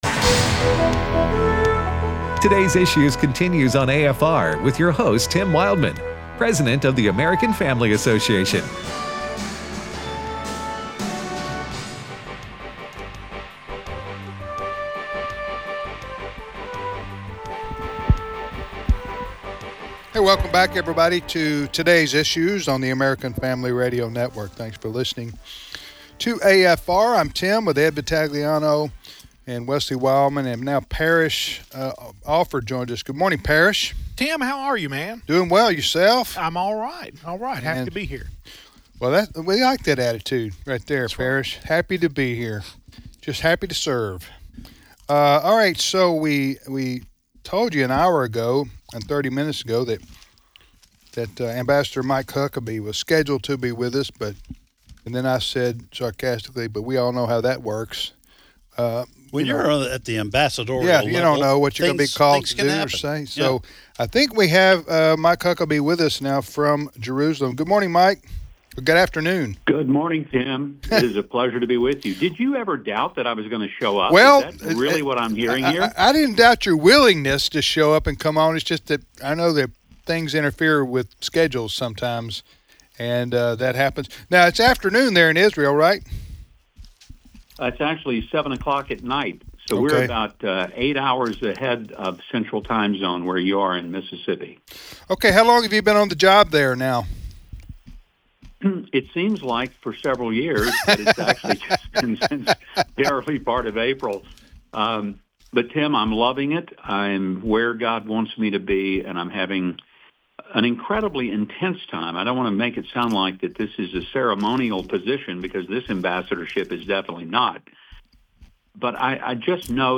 Also, Ambassador Huckabee joins the program to discuss his duties as being the U.S. Ambassador to Israel.